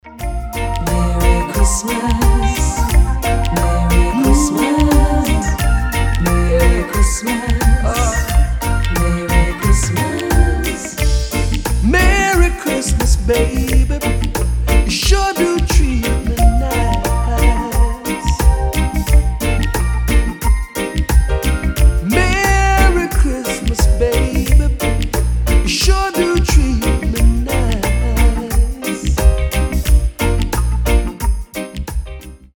• Качество: 320, Stereo
спокойные
приятные
праздничные